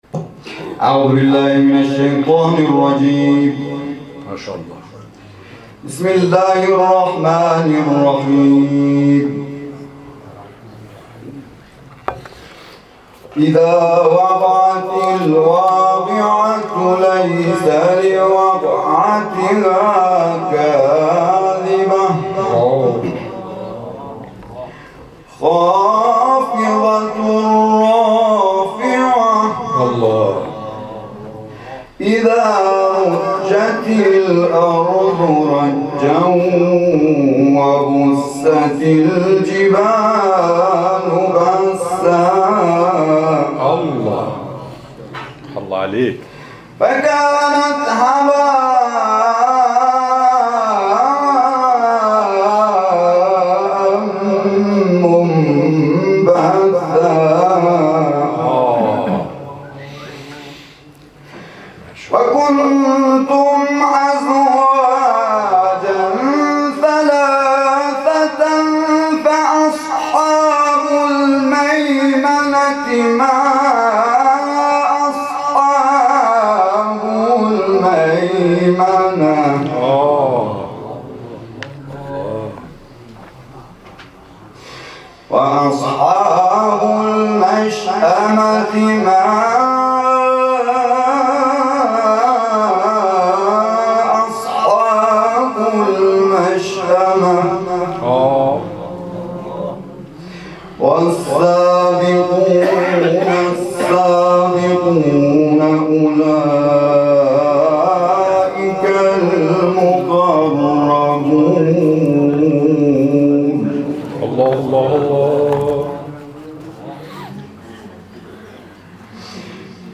هم‌خوانی